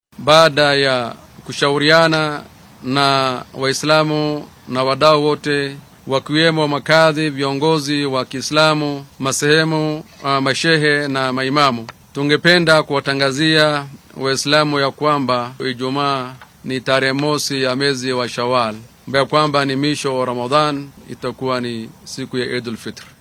Ku xigeenka qaadiga guud ee dalka Sukyaan Xasan Cumar ayaa isna xaqiijiyay in maanta ay ciid tahay .
Sukyaan-Xasan-Cumar-Ku-xigeenka-qaadiga-dalka.mp3